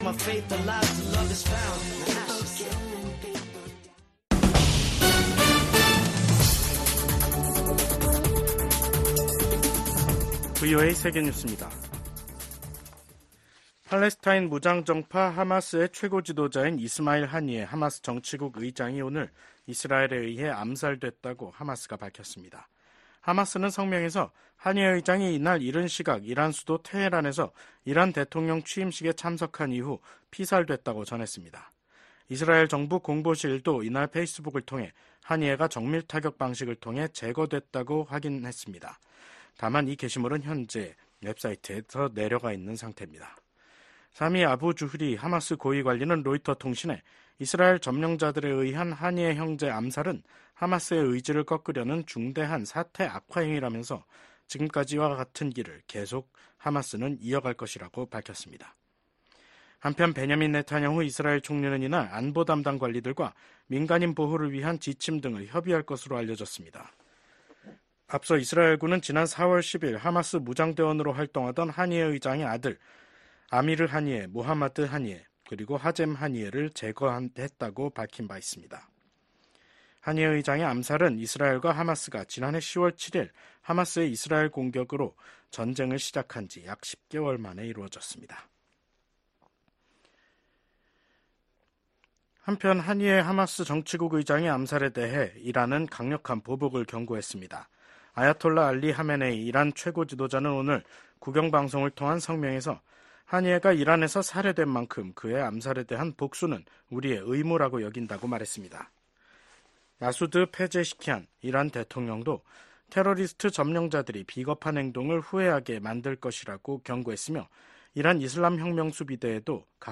VOA 한국어 간판 뉴스 프로그램 '뉴스 투데이', 2024년 7월 31일 3부 방송입니다. 중국과 러시아가 북한에 대한 영향력을 놓고 서로 경쟁하고 있다고 미국 국무부 부장관이 평가했습니다. 최근 몇 년간 중국, 러시아, 이란, 북한간 협력이 심화돼 미국이 냉전 종식 이후 가장 심각한 위협에 직면했다고 미국 의회 산하 기구가 평가했습니다.